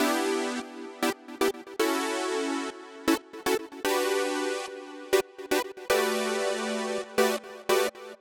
11 Chord Synth PT4.wav